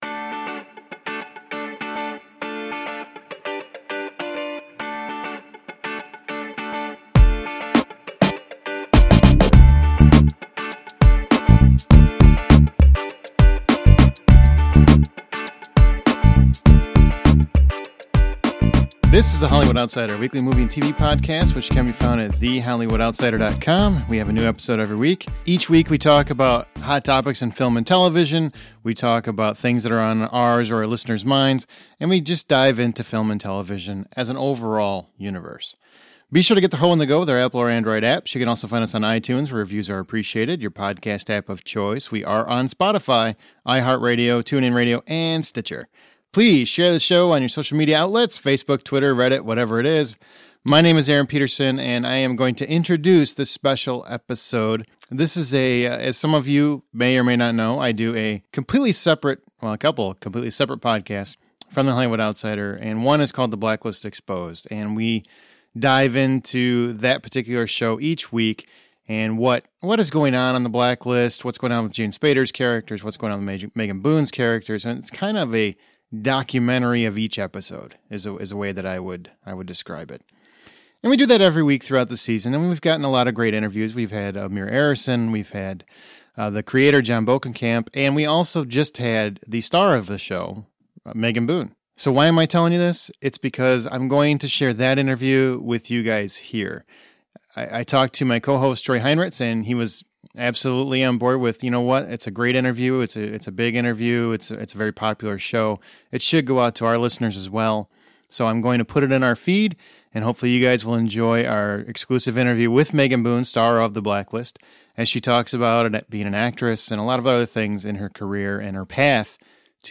Interview with Megan Boone | The Blacklist
Over the past three seasons one character has been through more than any other on The Blacklist. Today Megan Boone stops by to chat with the guys about Agent Elizabeth Keen, but more so about Megan herself.